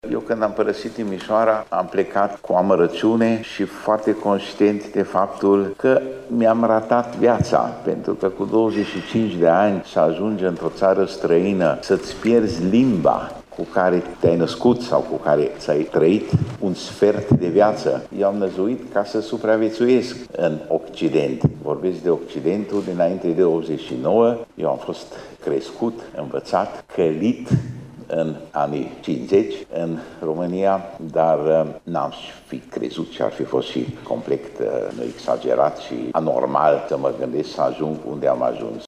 La festivitate au fost prezenţi, pe lângă reprezentanţii autorităţilor locale şi judeţene şi rectorii celor cinci universităţi de stat din Iaşi, precum şi conducerile Teatrului Naţional şi Operei Naţionale Române.